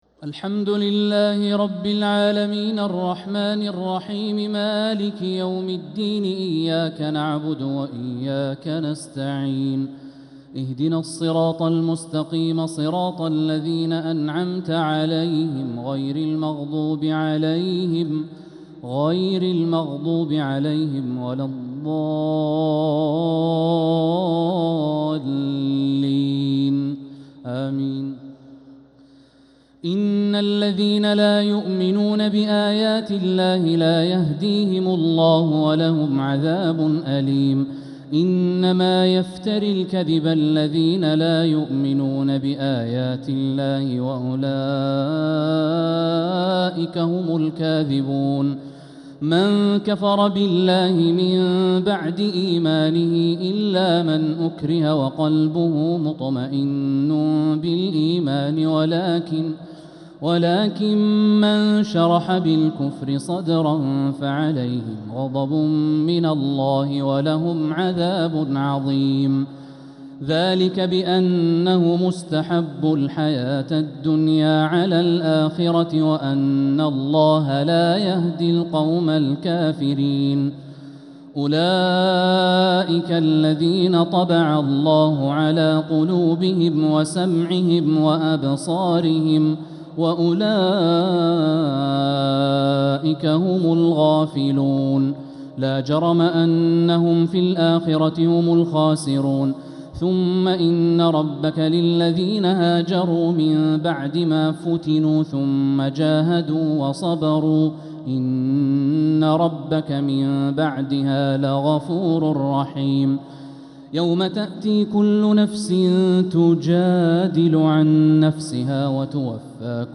تراويح ليلة 19 رمضان 1446هـ من سورتي النحل (104-128) و الإسراء (1-22) | Taraweeh 19th niqht Surat an-Nahl and Al-Israa 1446H > تراويح الحرم المكي عام 1446 🕋 > التراويح - تلاوات الحرمين